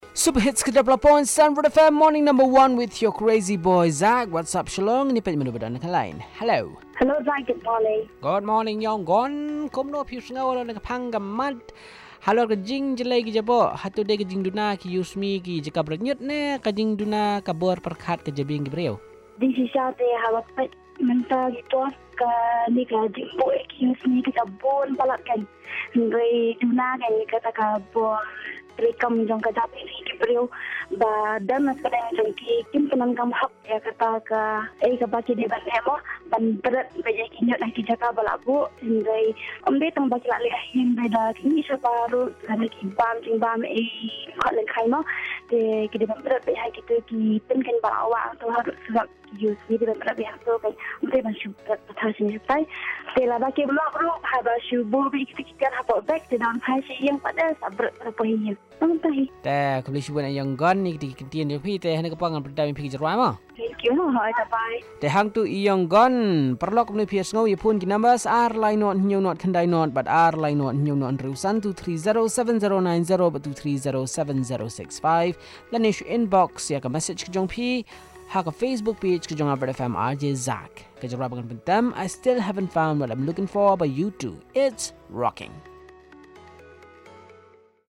Caller 1 on Caller 2 on Littering